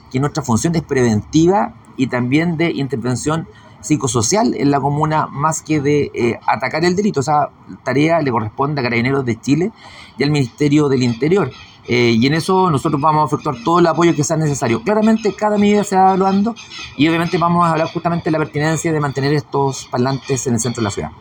Consultado el alcalde penquista subrogante, Aldo Mardones, dijo que la función del municipio es sólo preventiva con estos parlantes y endosó la responsabilidad al Ministerio del Interior y a las policías respecto de la seguridad. De todas maneras dijo que se tiene que evaluar la continuidad de estos parlantes.